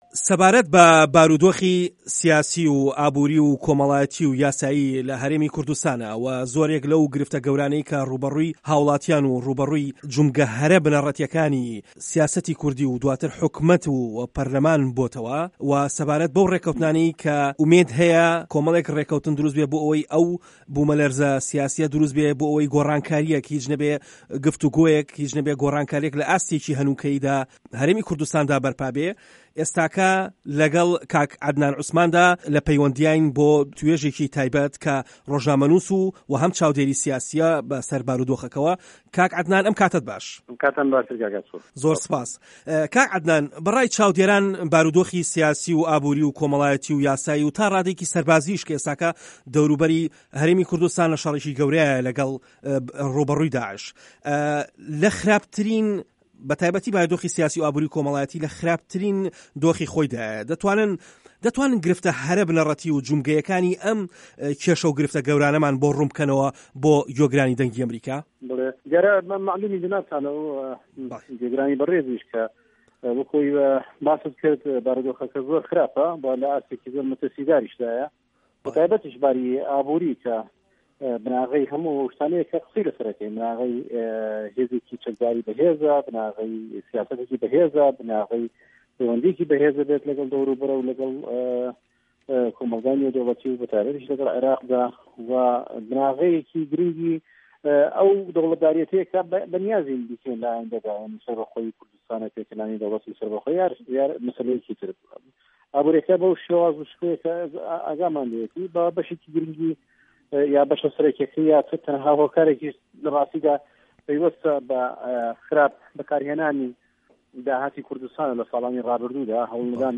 وتووێژ لەگەڵ عەدنان عوسمان